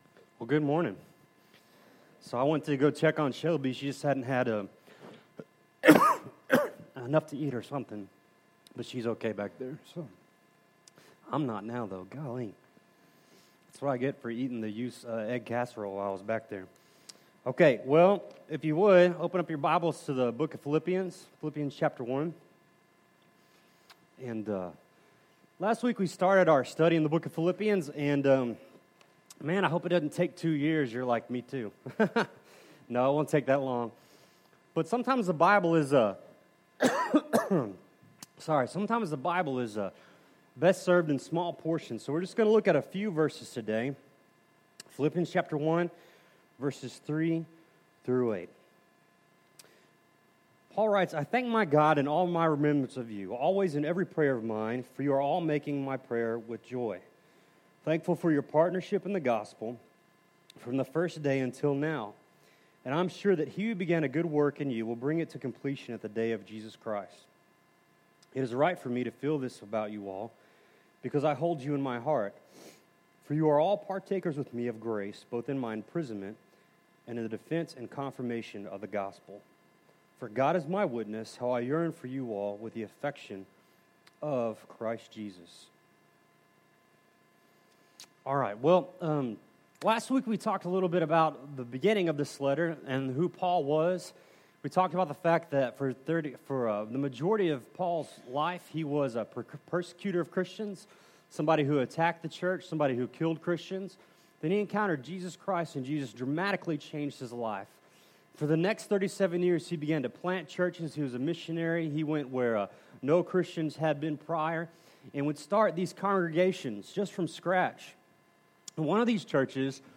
Philippians 1:3-8 Service Type: Sunday Morning Bible Text